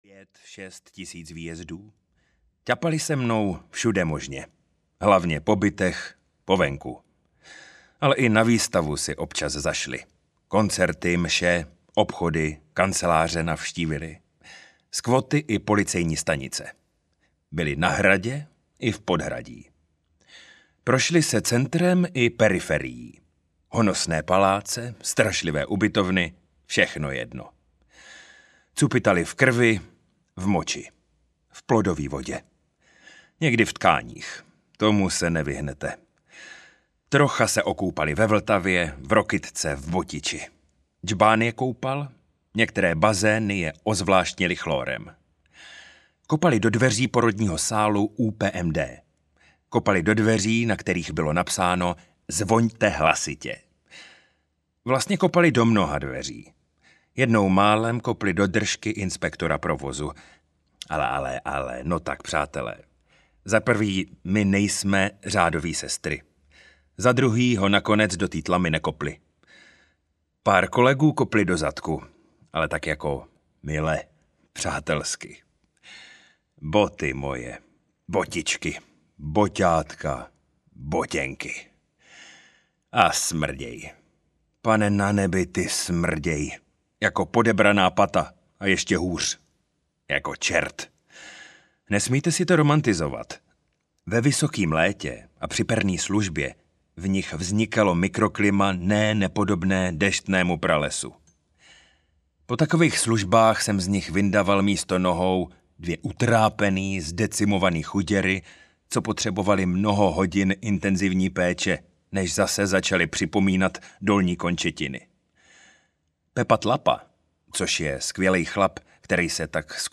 Bulbem záchranáře audiokniha
Ukázka z knihy